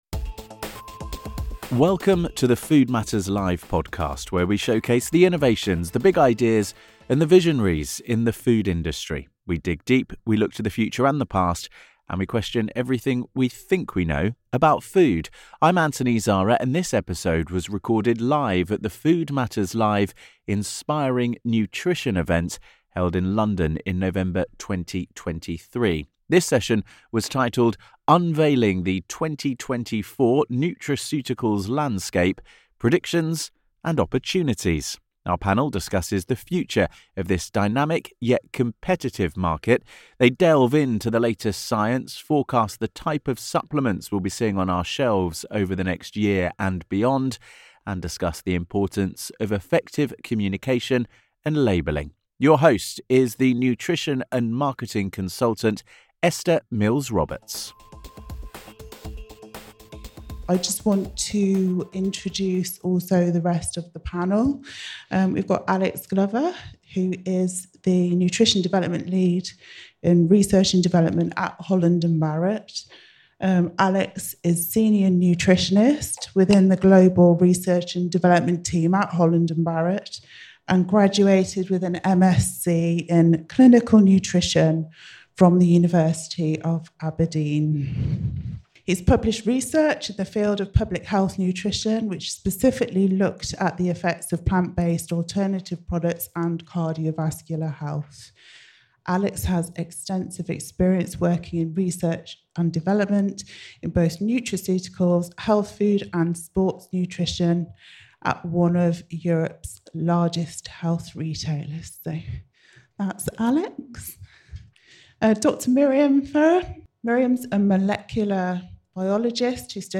In this episode of the Food Matters Live podcast, recording live at our Inspiring Nutrition Event in London in November 2023, we ask an expert panel to forecast where the nutraceuticals market is heading. They delve into the latest science, forecast the types of supplements we will be seeing on our shelves over the next year and beyond, and discuss the importance of effective communication and labeling.